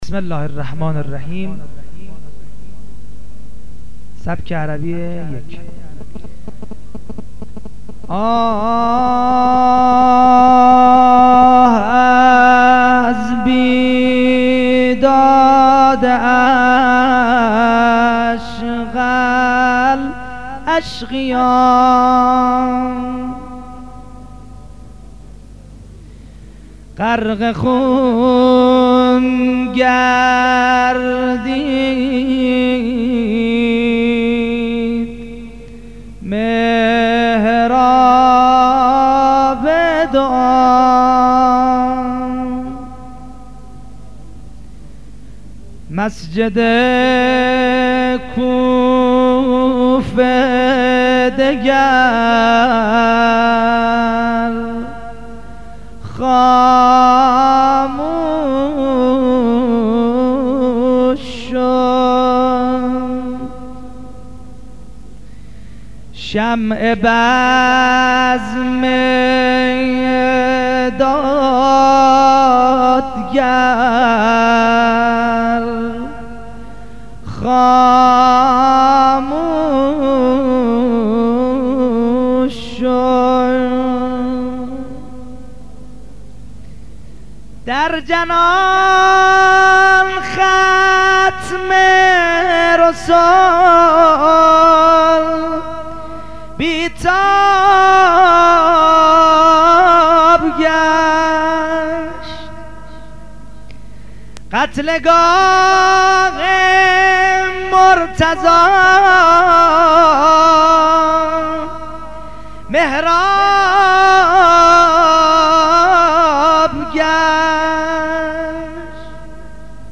درس اول ـ سبک عربی ـ001 آه ! از بيداد أشْقيَ الأشقِيا